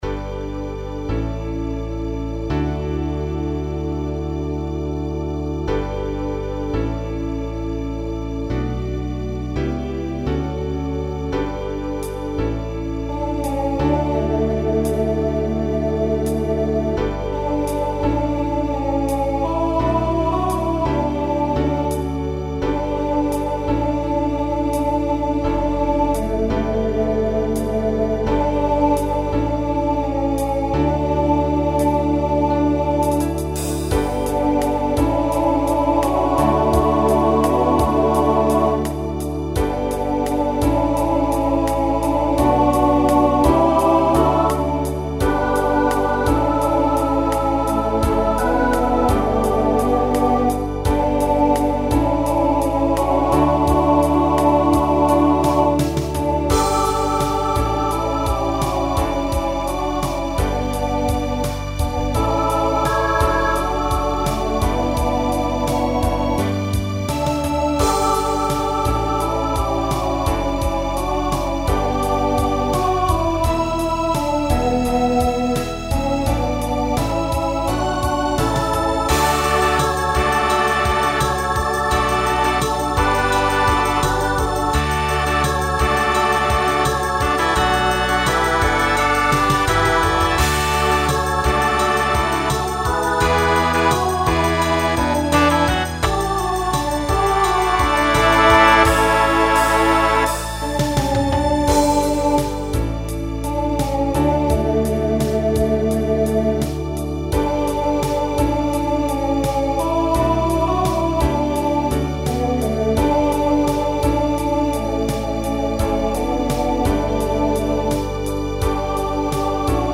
Ballad Voicing SSA